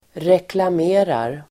Ladda ner uttalet
reklamerar.mp3